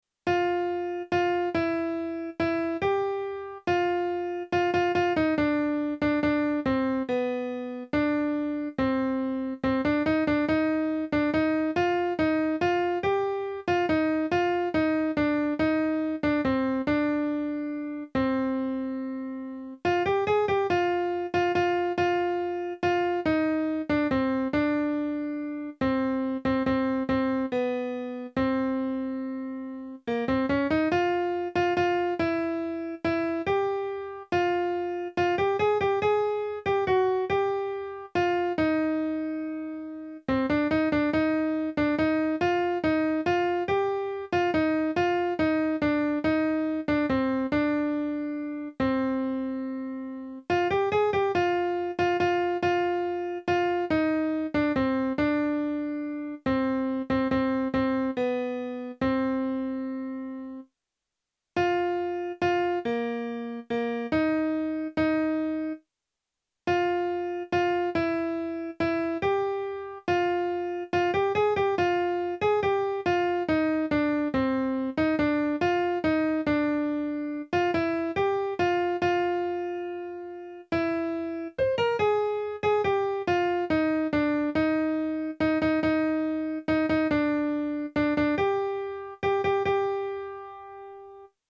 ALFONSINA-2a-VEU.mp3